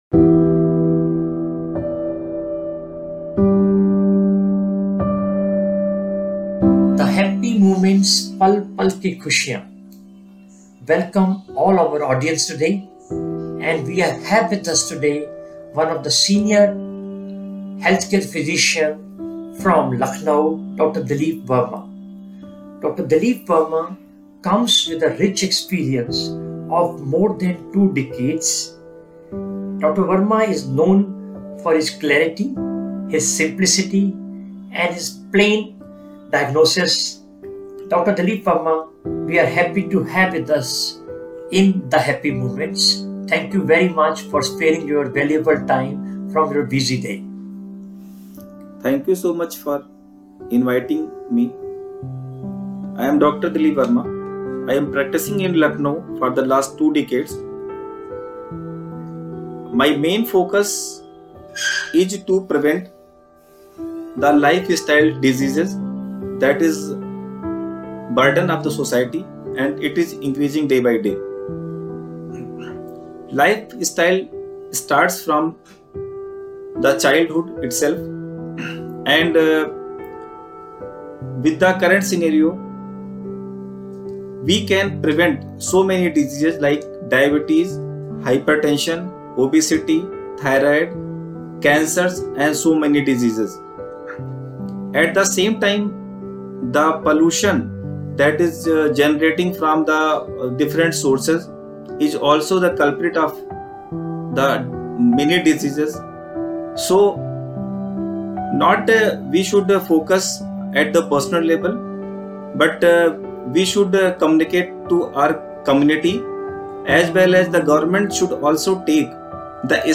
heart to heart conversation.